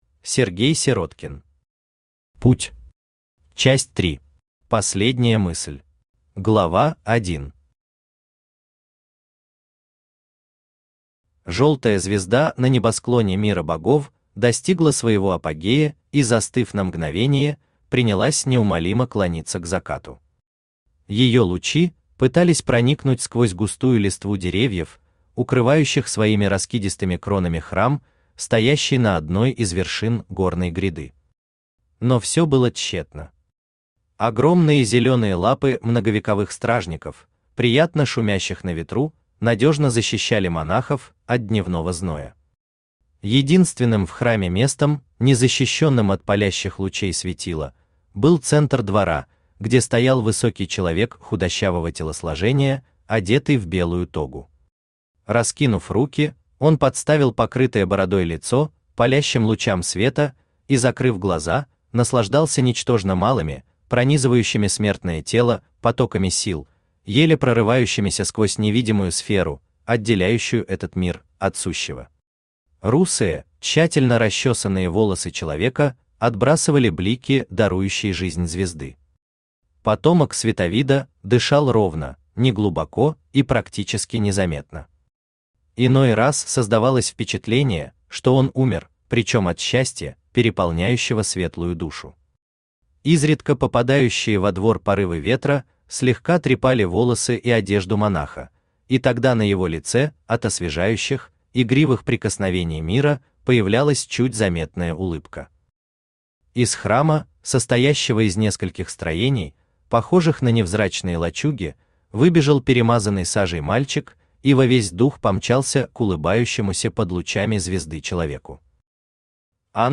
Аудиокнига Путь. Часть 3. Последняя мысль | Библиотека аудиокниг
Последняя мысль Автор Сергей Павлович Сироткин Читает аудиокнигу Авточтец ЛитРес.